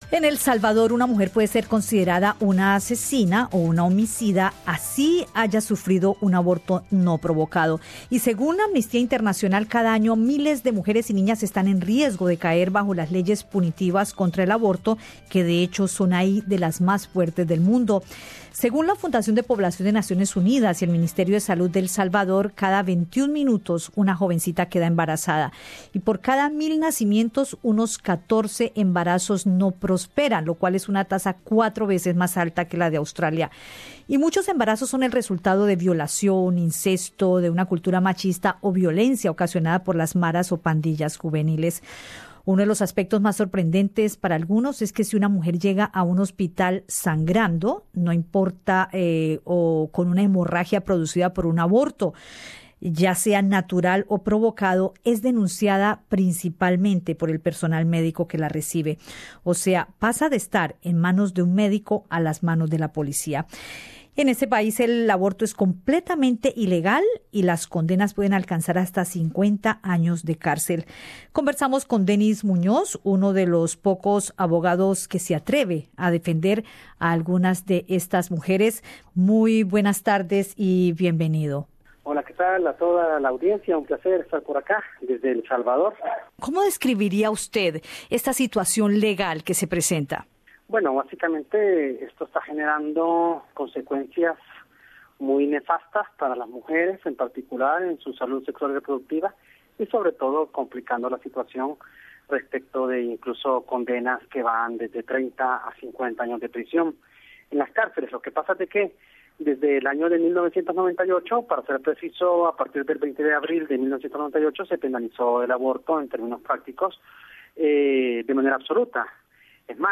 uno de los pocos abogados que se atreve a defender a algunas de estas mujeres.